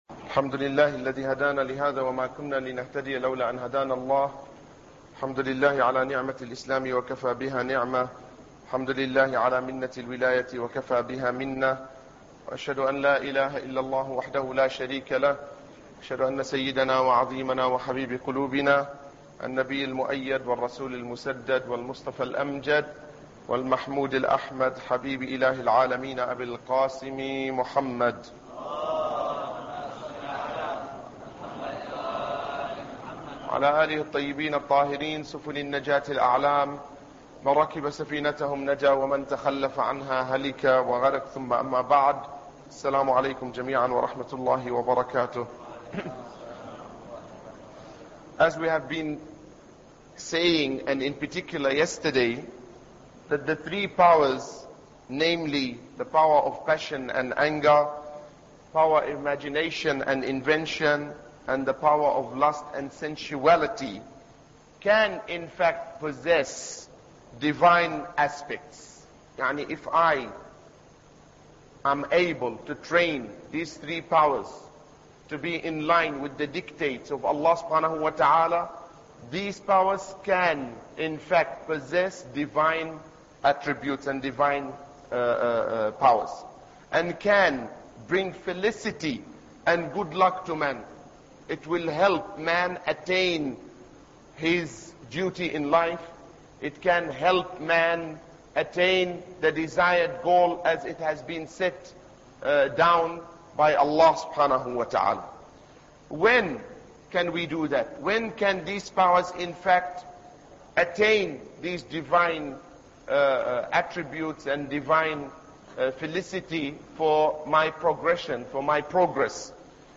Ramadan Lecture 6